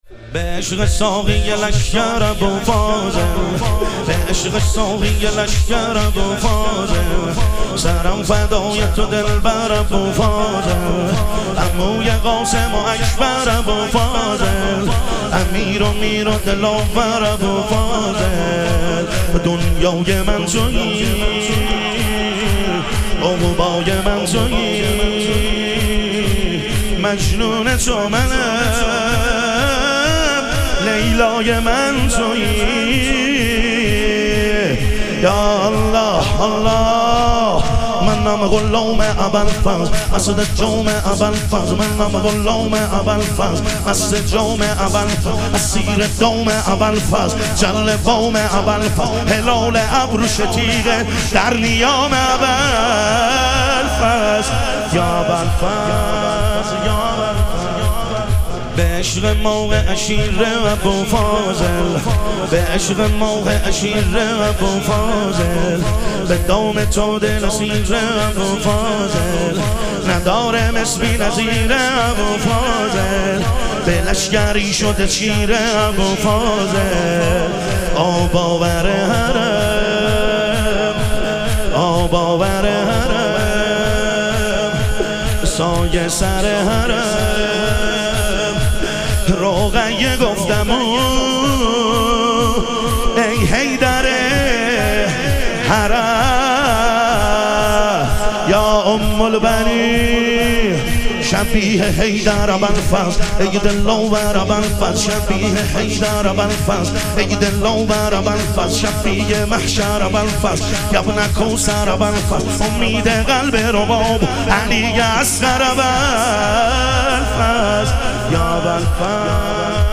شور
شب شهادت حضرت رقیه علیها سلام